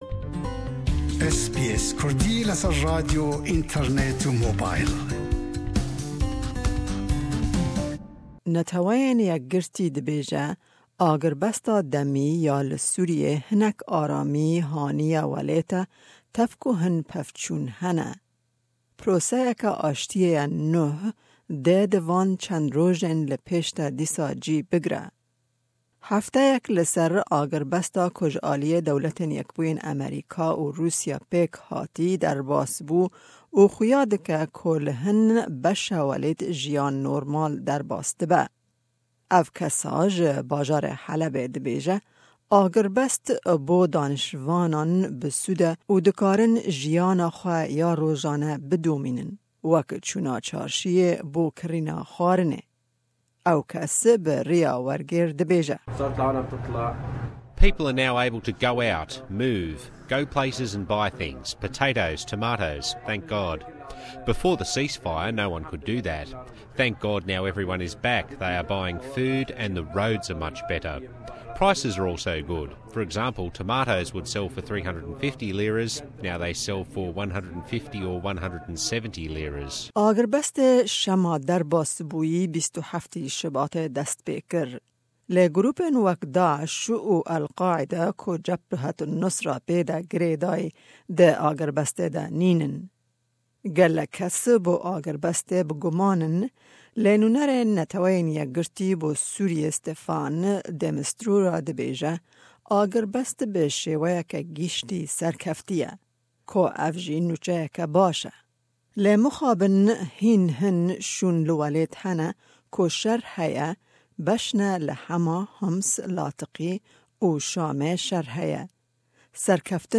Neteweyên Yekgirtî dibêje agirbesta demi ya li Sûriyê hinek aramî haniye welêt tev ku hin pevçûn hene. Proseyeke ashtiyê ye nuh dê di van çend rojên li pêsh de dîsa cîh bigre. Raport bi Îngilîzî û Kurdiye.